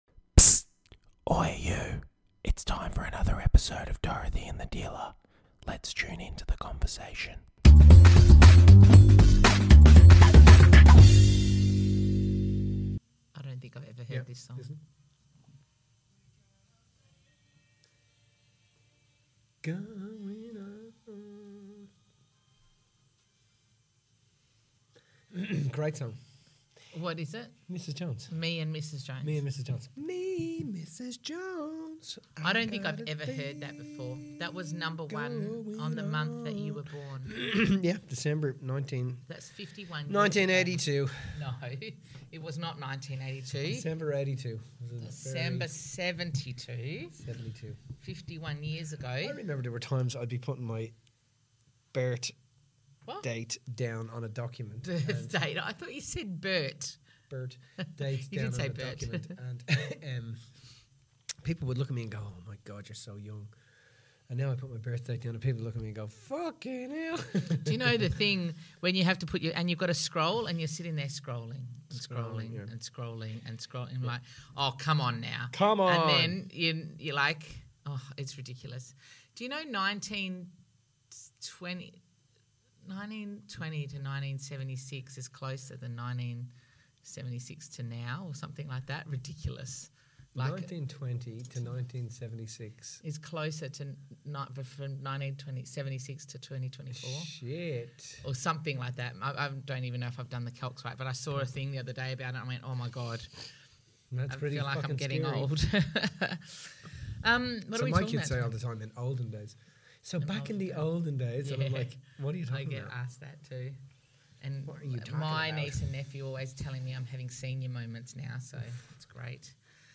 In todays day and age with the increase in social media use, we are noticing a shift in our ability as a society to critically think and deduce false information from what is real. In this conversation